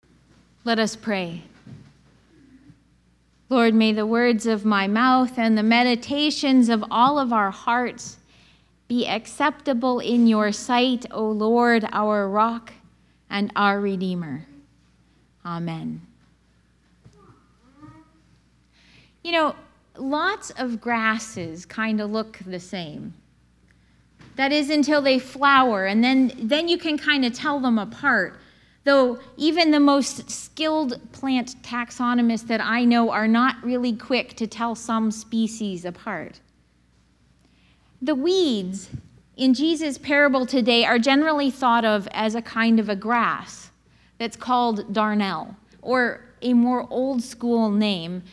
Audio of sermon: